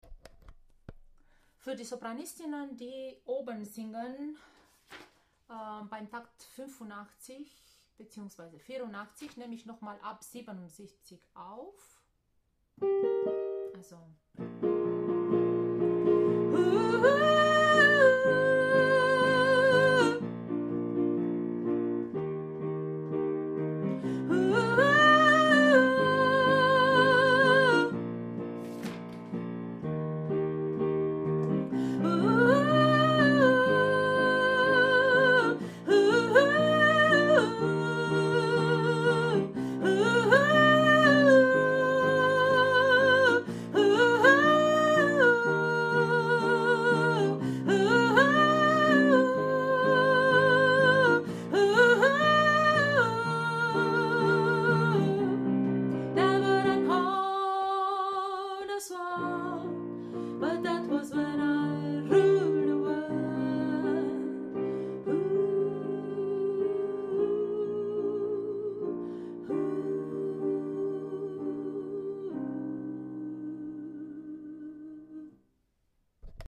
Viva-La-Vida-Sopran-Plus.mp3